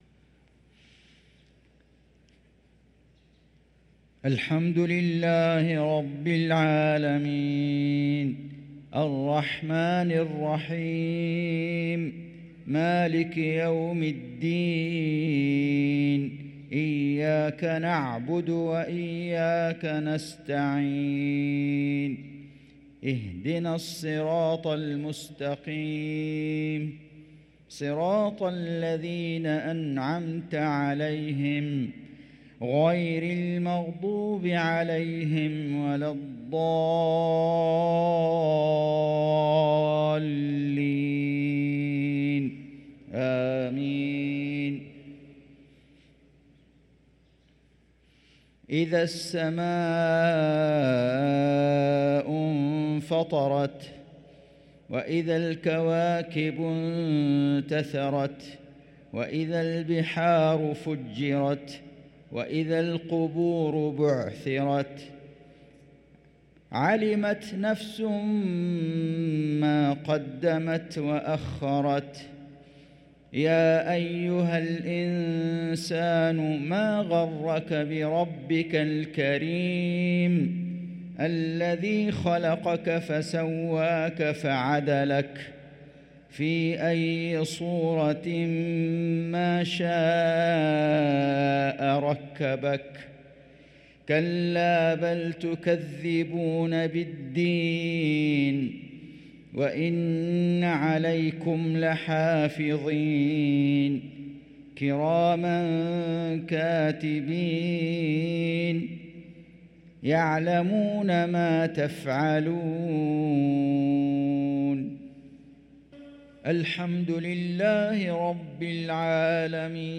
صلاة المغرب للقارئ فيصل غزاوي 13 صفر 1445 هـ
تِلَاوَات الْحَرَمَيْن .